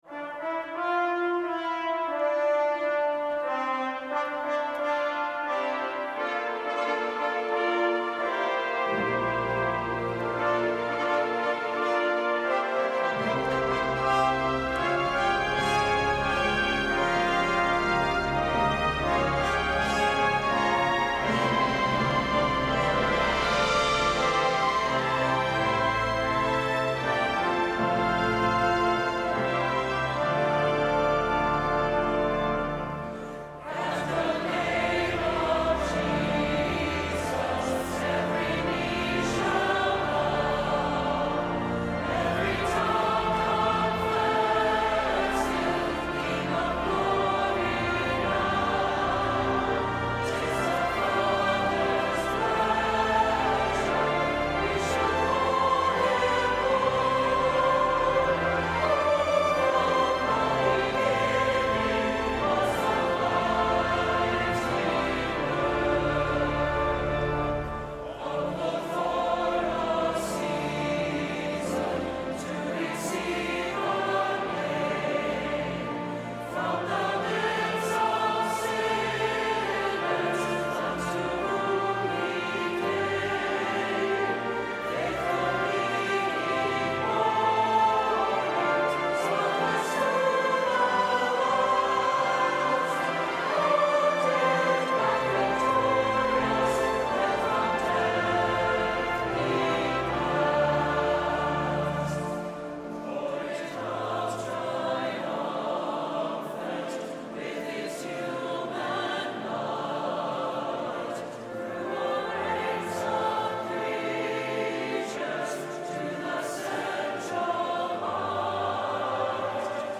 Full perusal score for choir and orchestra
Mp3 Download • Live Rec.